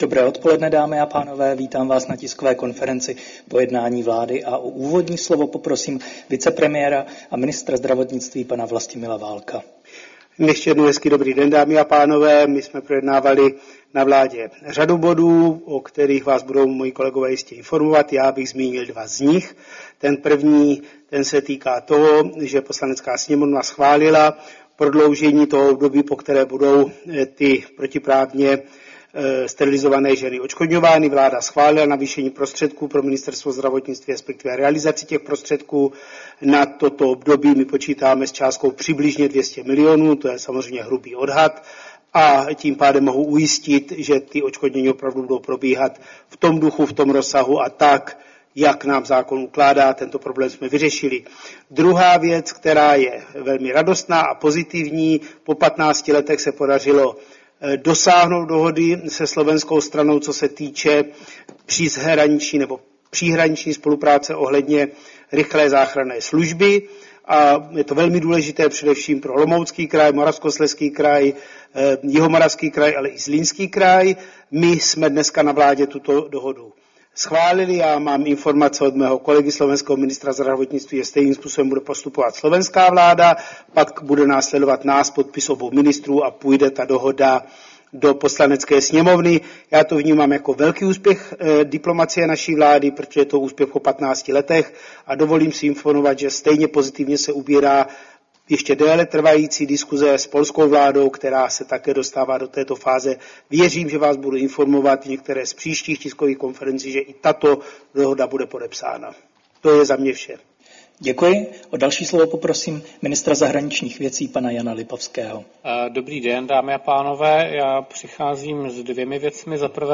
Tisková konference po jednání vlády, 28. května 2025